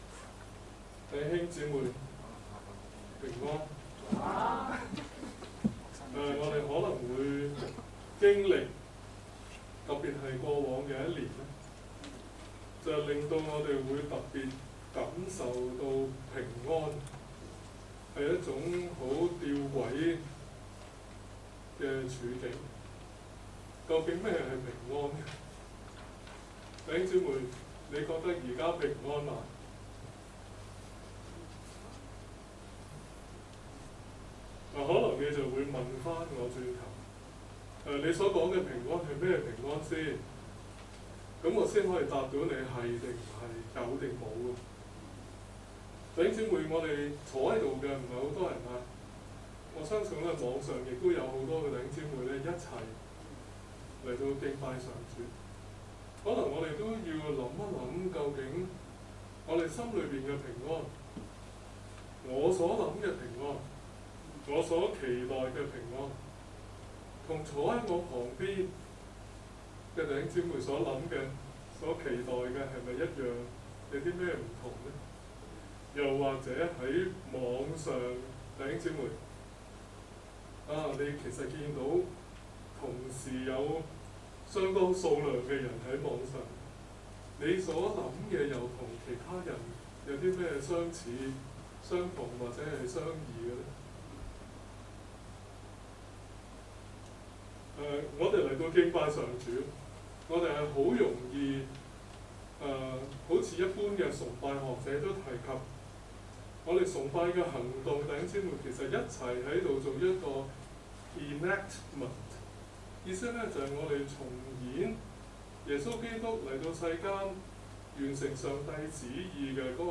2020年5月24日崇拜
講道：不要以為奇怪 讀經：彼前 4 ： 12-14 、 5 ： 6-11